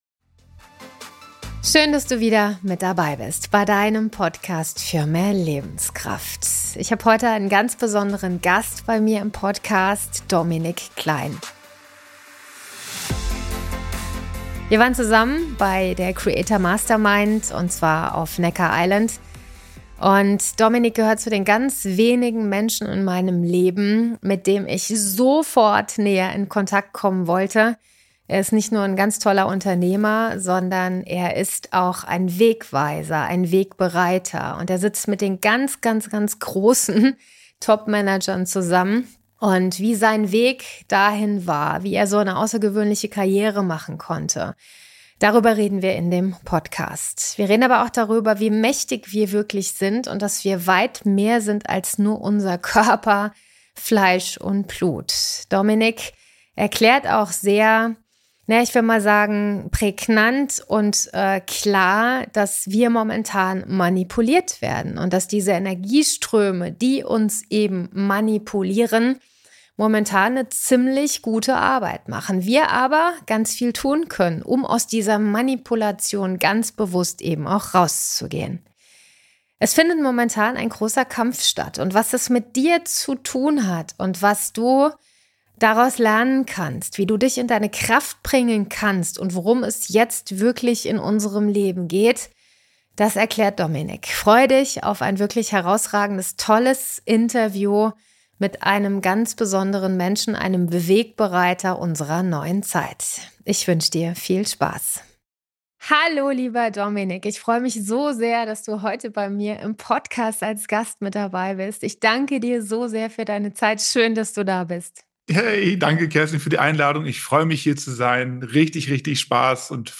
Ein zentrales Thema unseres Gesprächs: Manipulation auf globaler Ebene. Wir sprechen darüber, warum aktuell viele Entwicklungen kein „Zufall“ sind und warum genau jetzt der Moment gekommen ist, aufzuwachen. Es geht um Selbstermächtigung.